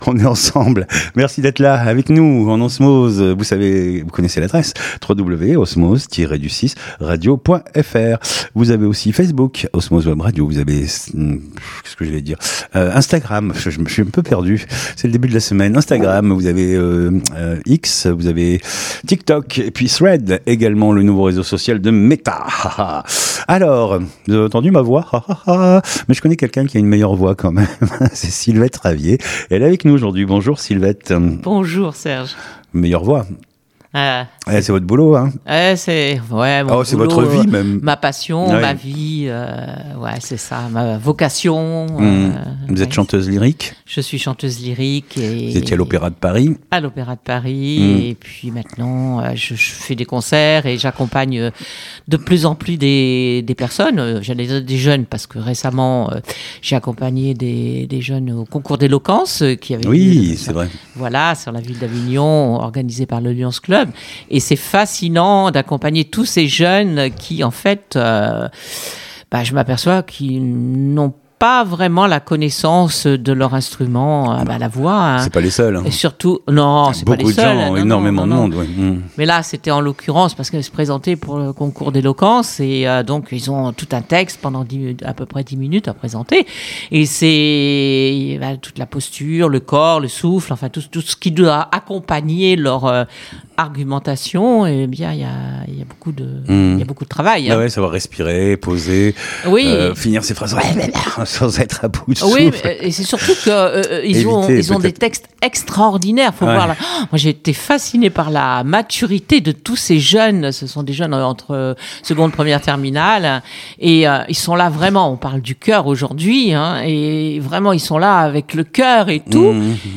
Elle nous en parle dans cet entretien.